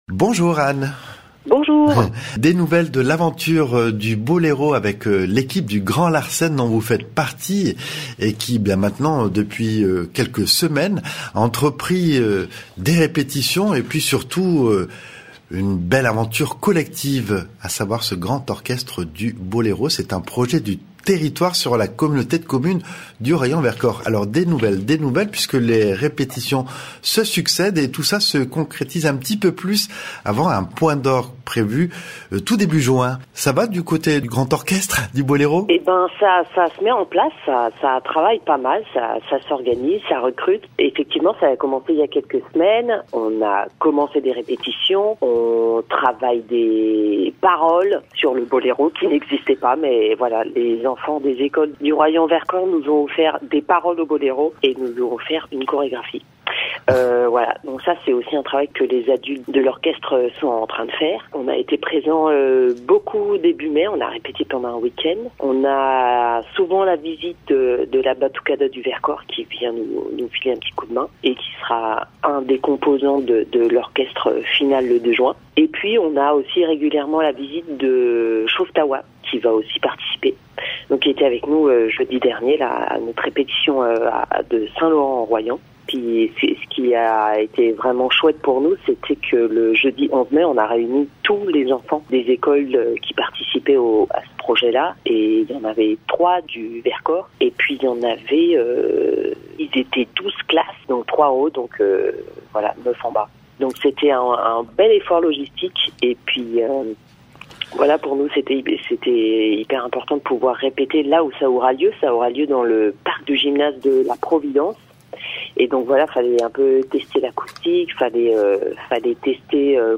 Elle revient notamment sur le projet de Grand Orchestre du Boléro de Ravel. L’interview est suivie d’un extrait de répétition en compagnie des élèves.